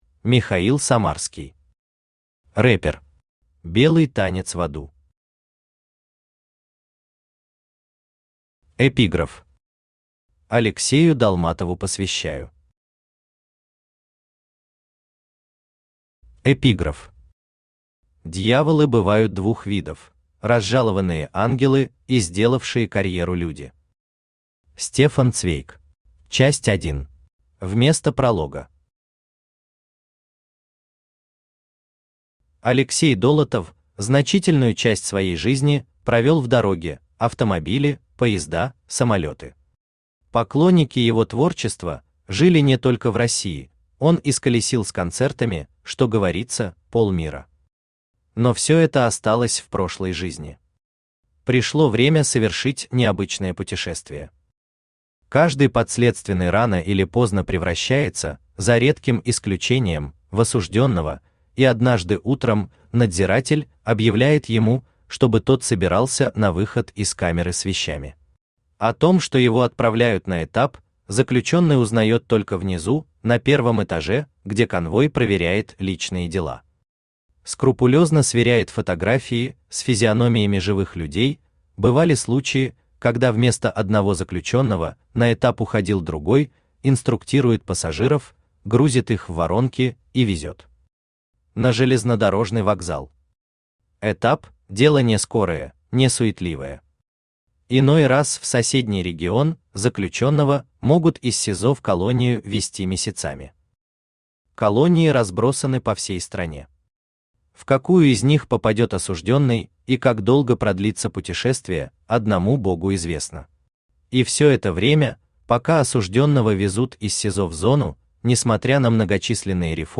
Белый танец в аду Автор Михаил Самарский Читает аудиокнигу Авточтец ЛитРес.